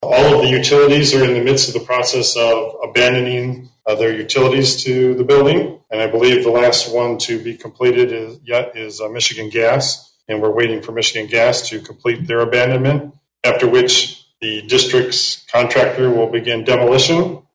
Coldwater City Manager Keith Baker said during Monday’s City Council meeting that the Coldwater Community School District has gotten a demolition permit.